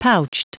Prononciation du mot pouched en anglais (fichier audio)
Prononciation du mot : pouched